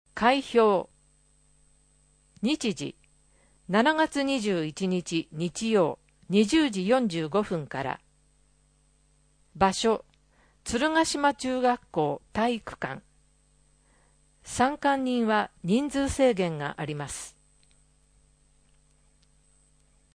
14 奥付（47秒）声の広報つるがしまは、「鶴ヶ島音訳ボランティアサークルせせらぎ」の皆さんが「広報つるがしま」の内容を音訳し、「デイジー鶴ヶ島」の皆さんがデイジー版CDを製作して、目の不自由な方々へ配布をしています。